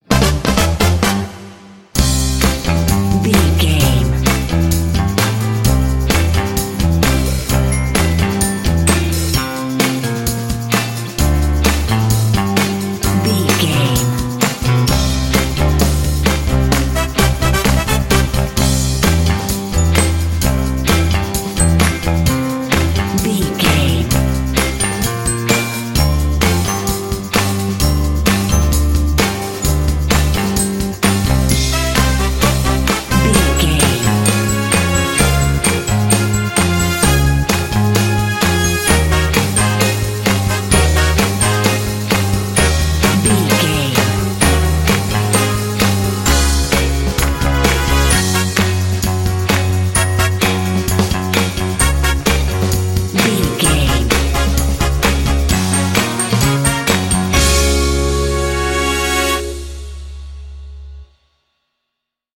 Ionian/Major
energetic
playful
uplifting
lively
cheerful/happy
piano
trumpet
electric guitar
brass
percussion
bass guitar
drums
classic rock